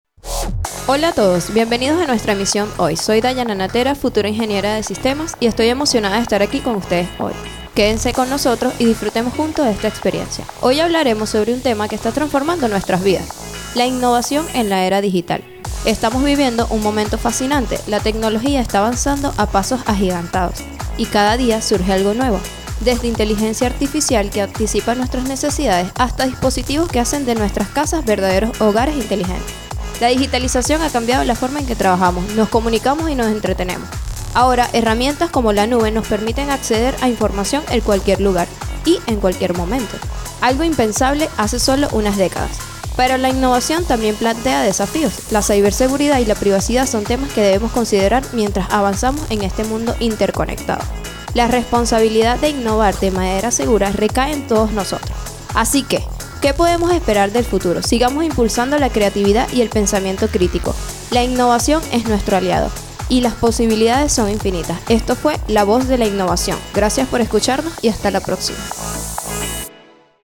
Estudiantes de la carrera de Ingeniería de Sistemas